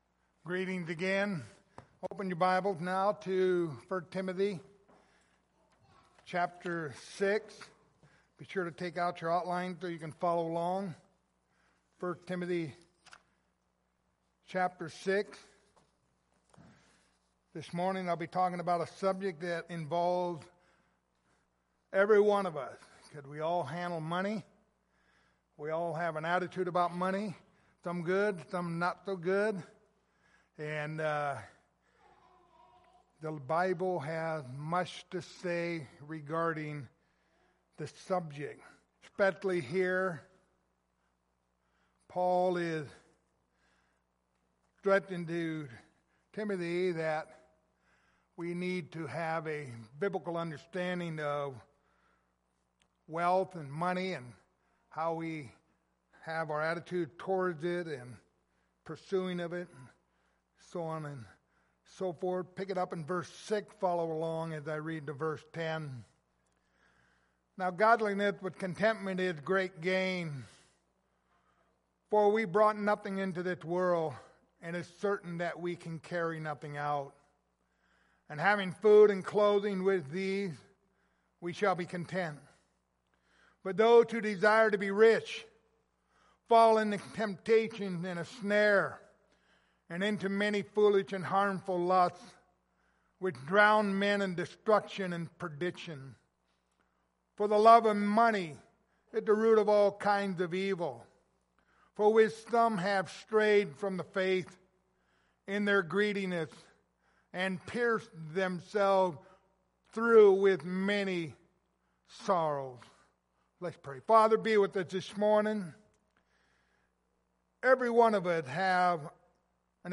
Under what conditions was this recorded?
Pastoral Epistles Passage: 1 Timothy 6:6-10 Service Type: Sunday Morning Topics